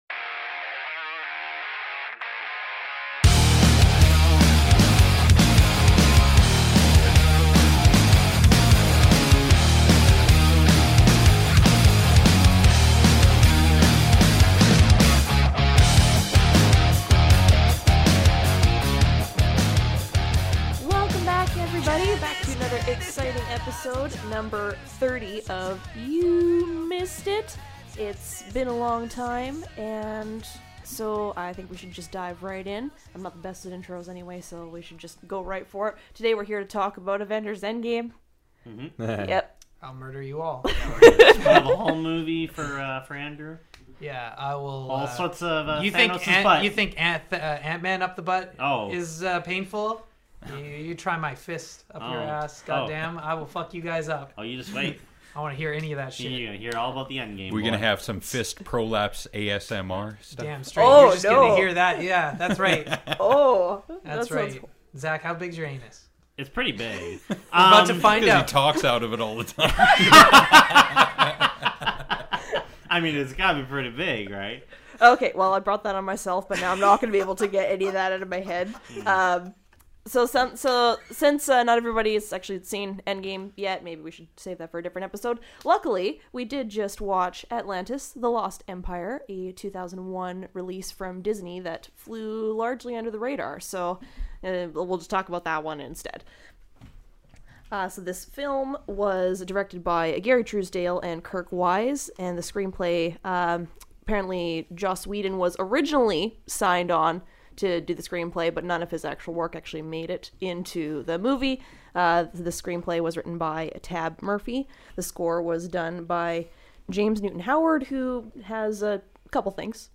You Missed it?: A weekly podcast in which five cinephiles watch lesser-known films that were overlooked upon their initial release. Each episode, one of the hosts selects a movie to watch that they feel has not received the credit it deserves; afterwards everyone discusses and offers their opinions and their analyses to determine whether or not the movie is indeed underrated.